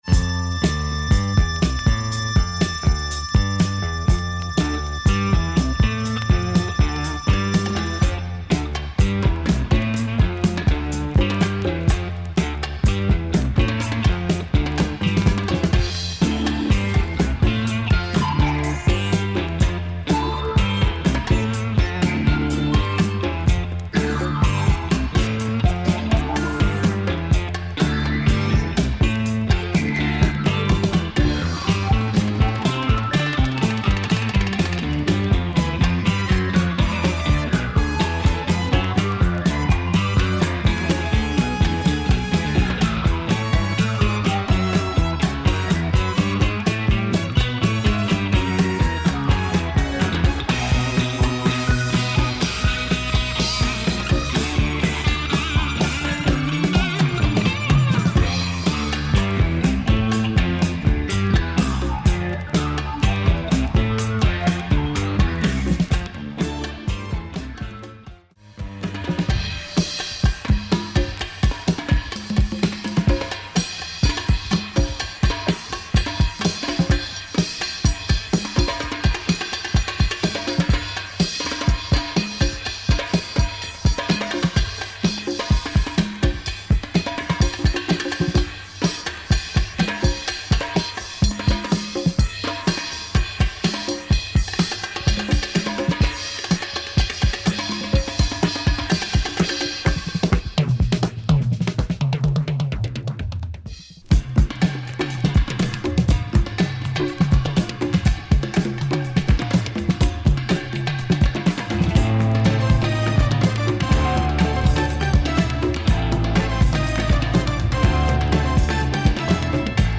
Monster psych afro funk with heavy breaks !